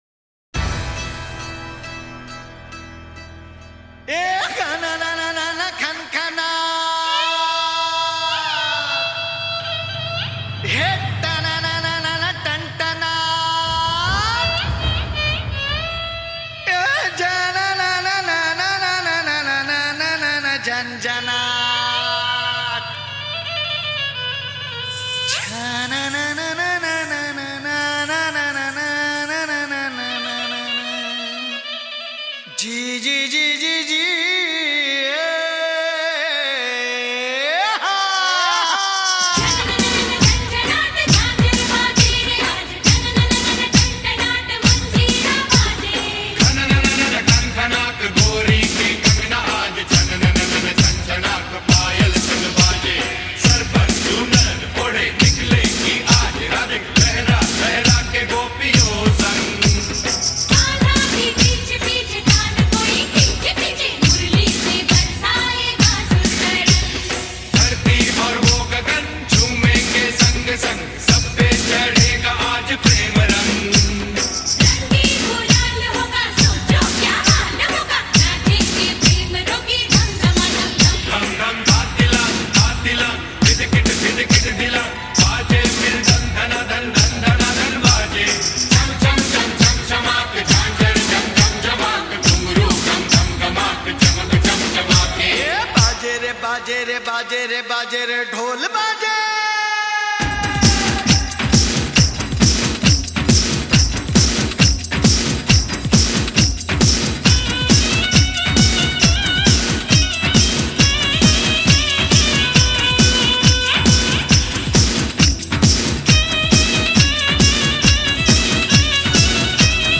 Navratri Special Song